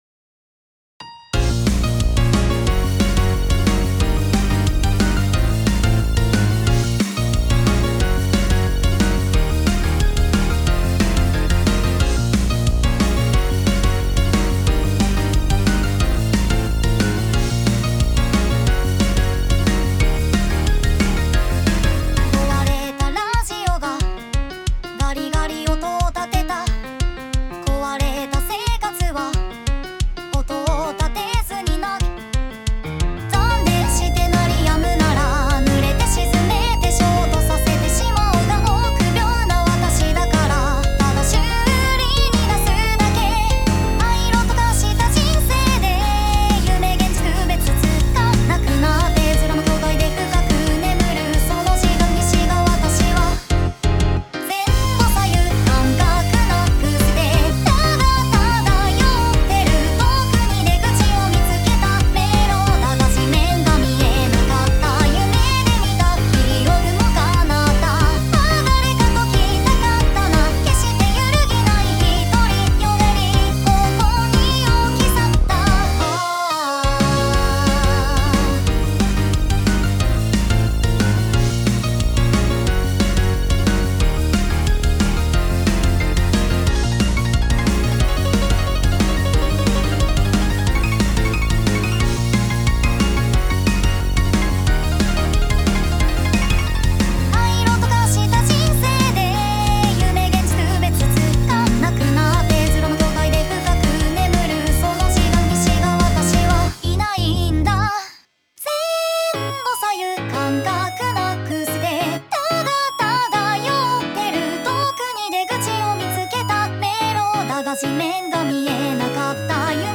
こちら歌入りです🥺
アクションゲームに合いそうでいいね
いいねぇ🥺なんか曲にすごいしっくりくるボーカル
サビの疾走感すこ🤤